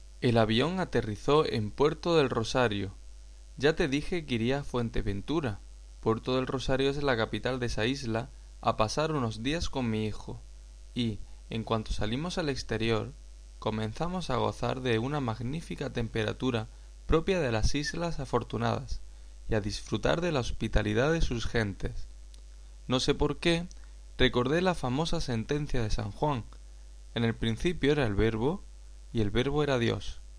Dictado